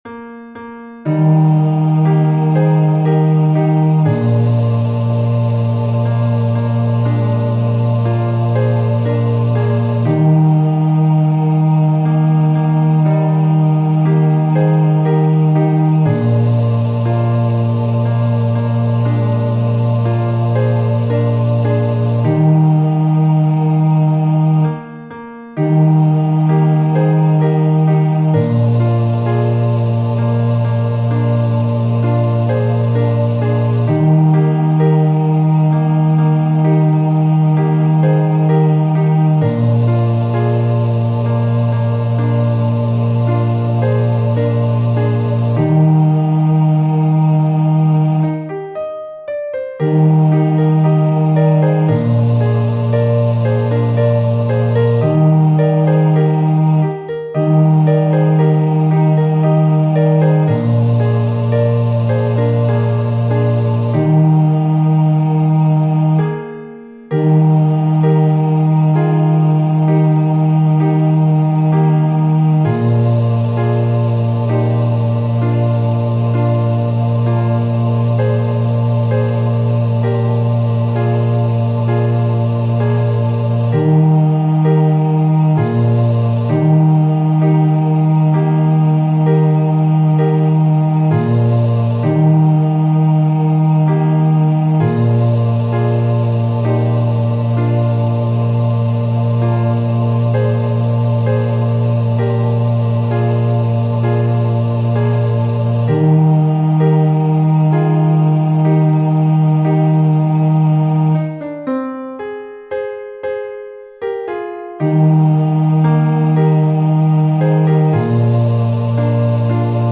Demos zum Herunterladen